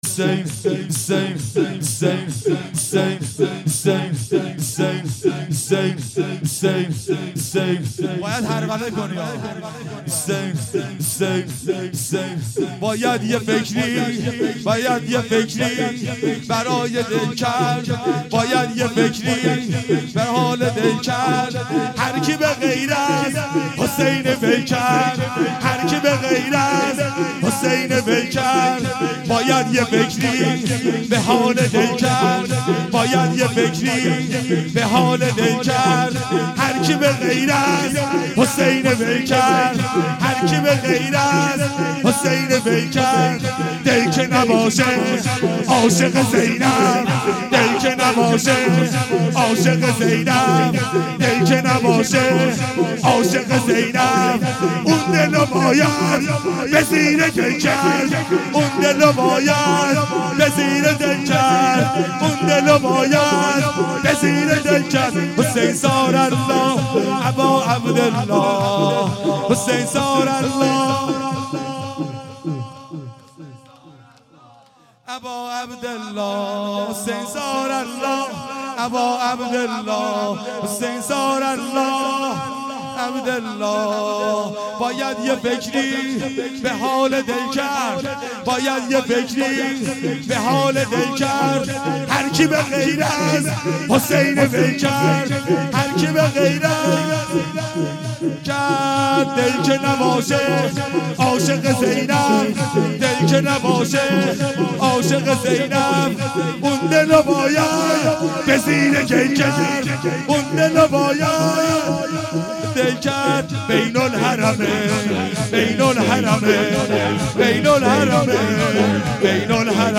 اصوات مراسم سیاهپوشان ودهه اول محرم۹۷هییت شباب الحسین